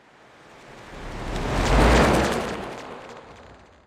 Скоростное ускорение